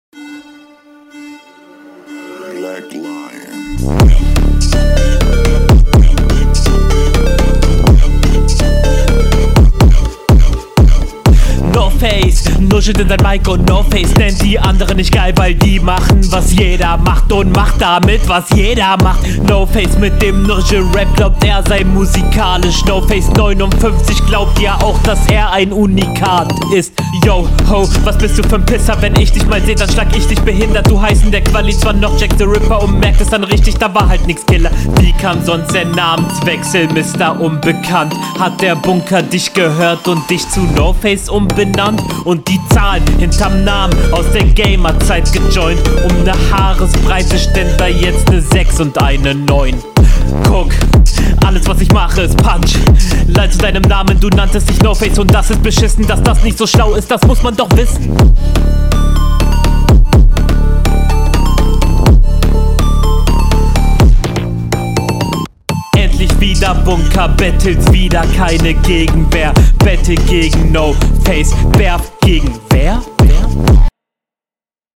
Einstieg leider ein bisschen verkackt, der Flow danach sehr cool, klingt aber stellenweise etwas unsicher …
Finds flowlich solide.